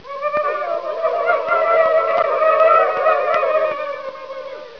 inhoot.wav